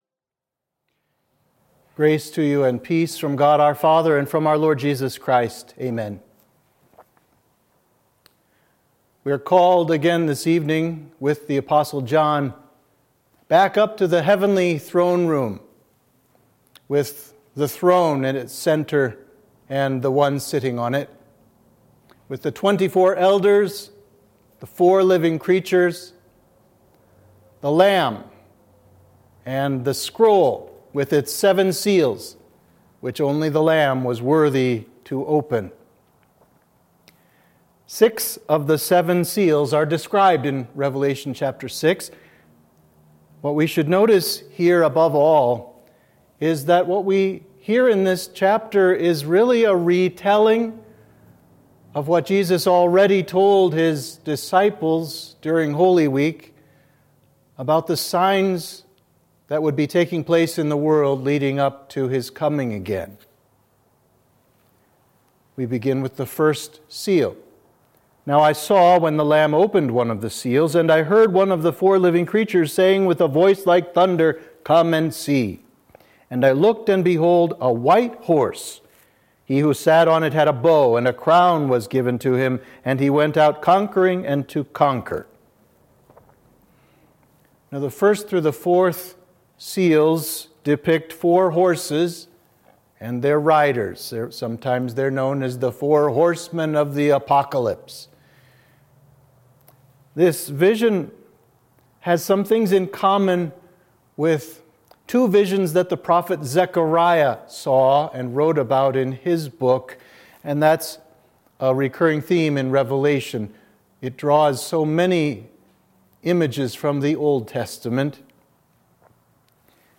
Sermon for Midweek of Judica – Lent 5